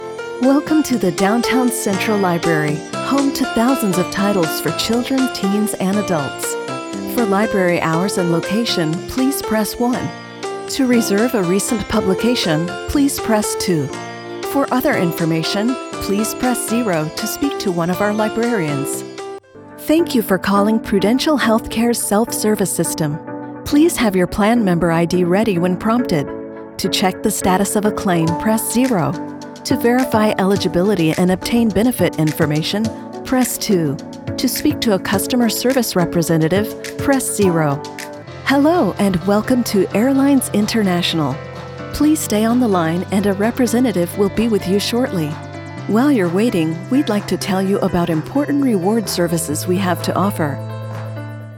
English (American)
Commercial, Natural, Reliable, Friendly, Warm
Telephony